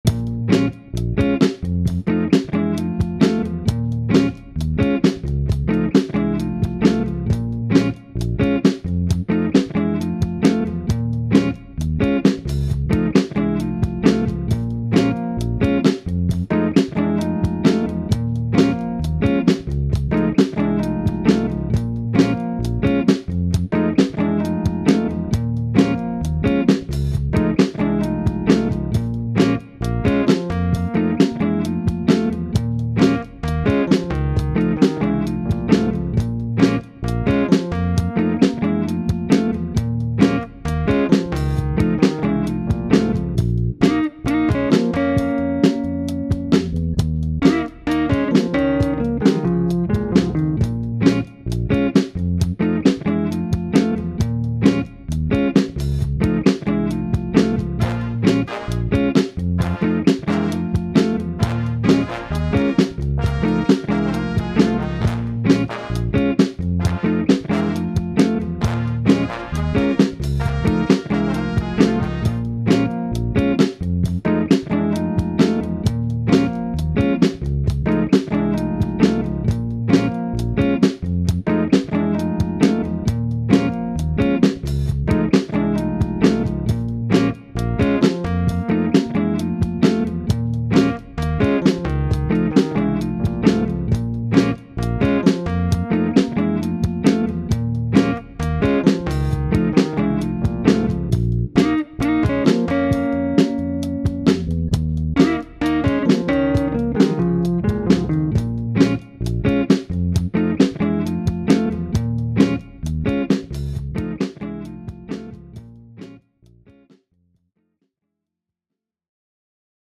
Het hoofdthema op gitaar bestaat uit de volgende akkoorden
C#/A# F#7
Intro / Intro + Rhodes / Couplet (Melody on Rhodes) 3x / Couplet (Melody on Rhodes doubled by Guitar) / Brass Bridge / Intro + Rhodes / Couplet (Melody on Rhodes) 3x / Couplet (Melody on Rhodes doubled by Guitar)